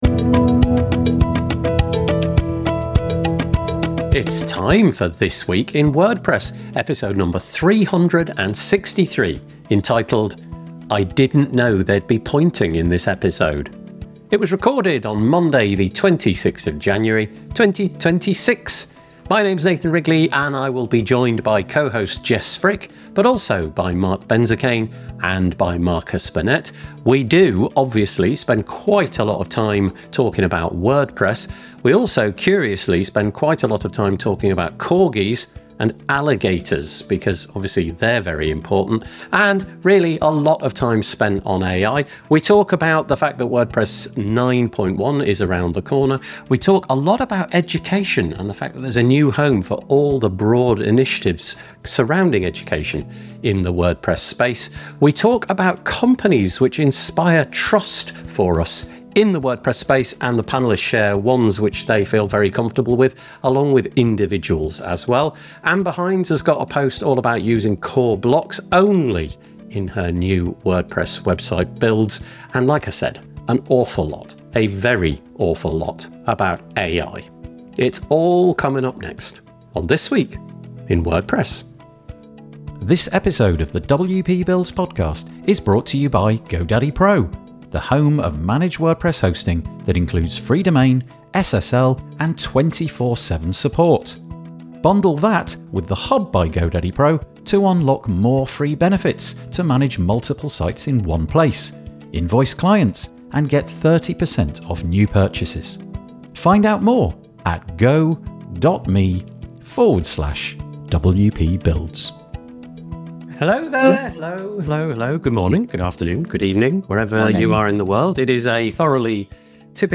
This episode covers a range of WordPress-related topics, including the upcoming release of WordPress 6.9.1, the launch of a new centralised home for WordPress education initiatives, and trusted companies and individuals in the WordPress ecosystem. The panel also dives into the evolving role of AI in WordPress, discussing benchmarks, industry changes, and the impact of AI-driven advertising.